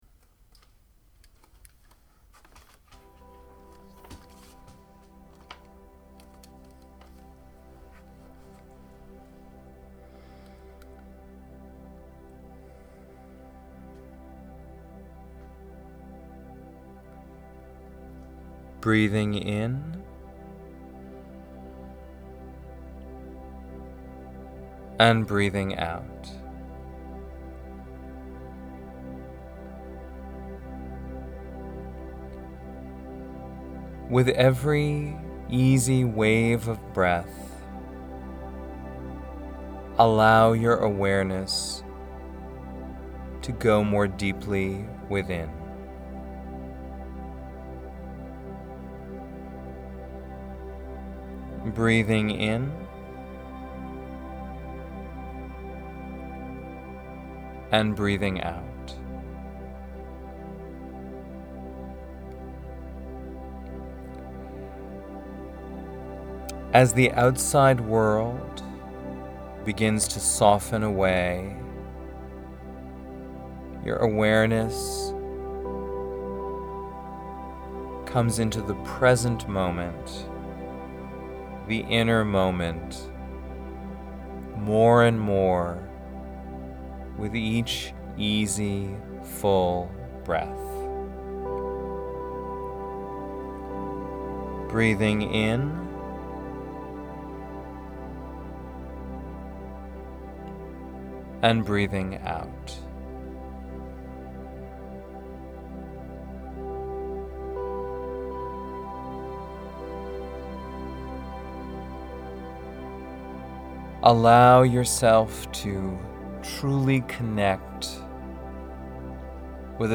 Thank you for your short and effective meditations.
Quantum-Leap-Meditation-1.mp3